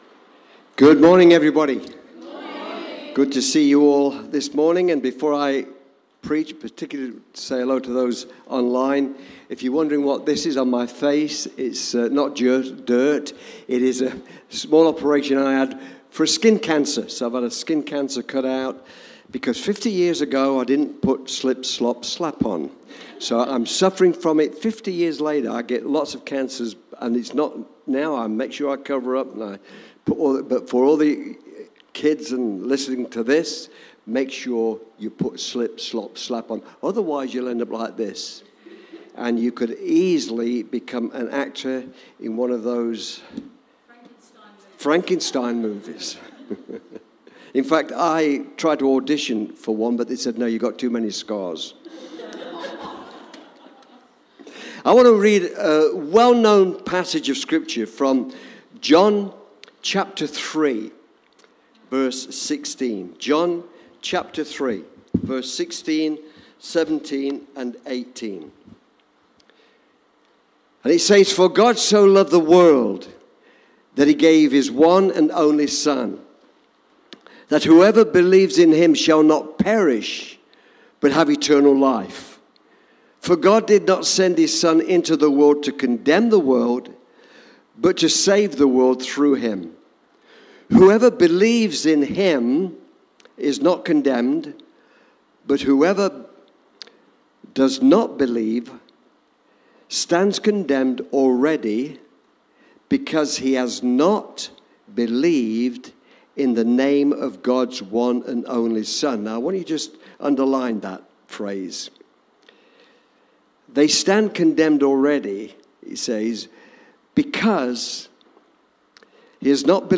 Preached on 6th November 2022.